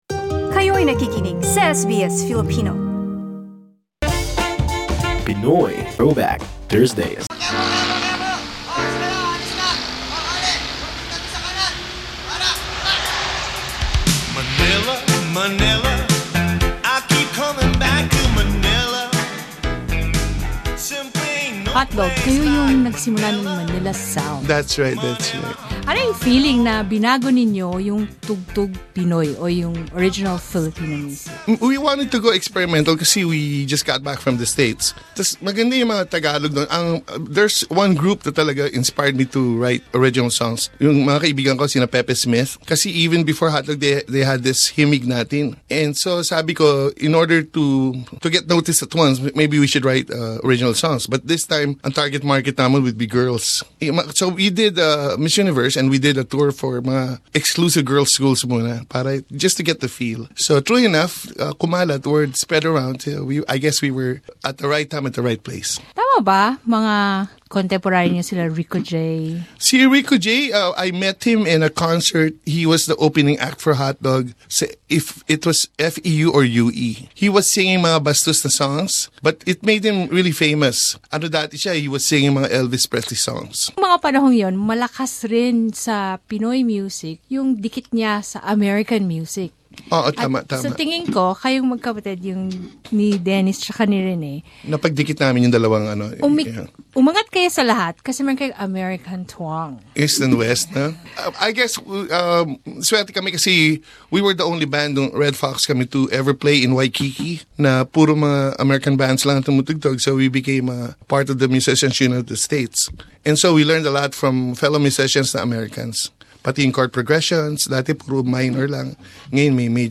In the said interview